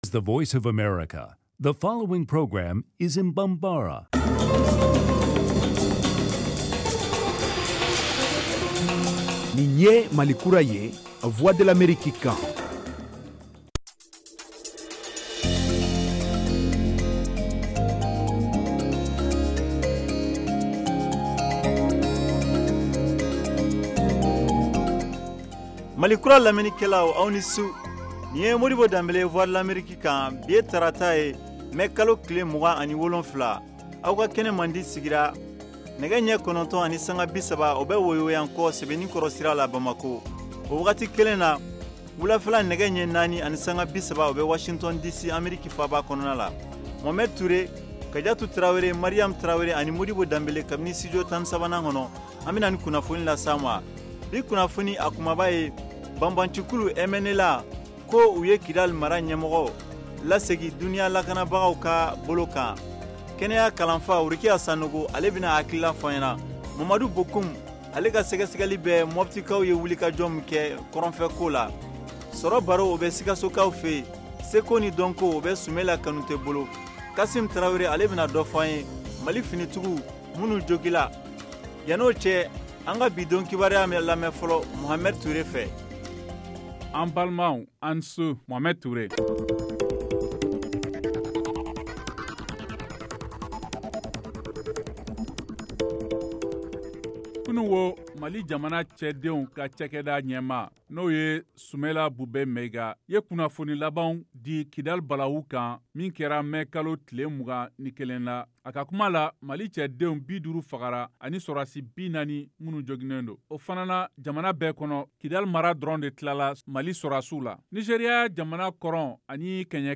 Emission quotidienne en langue bambara
en direct de Washington. Au menu : les nouvelles du Mali, les analyses, le sport et de l’humour.